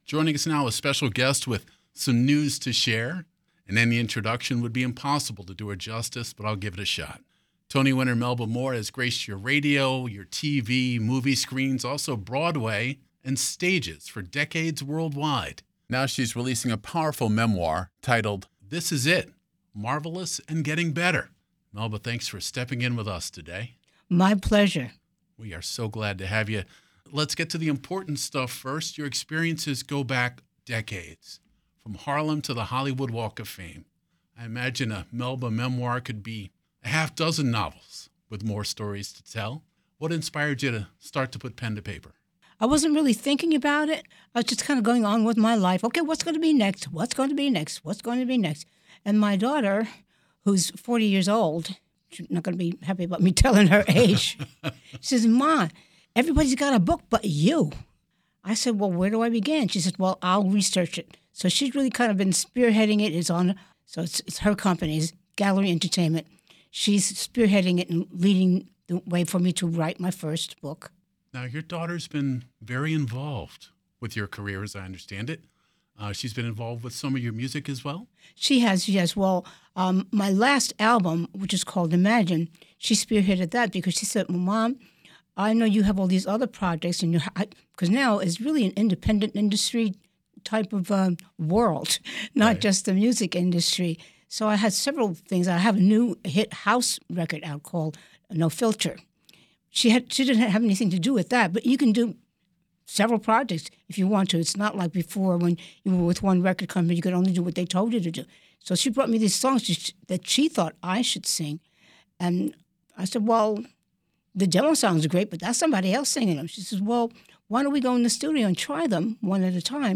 Singer Melba Moore joins WTOP to dish on her Broadway career, hit records and new memoir - WTOP News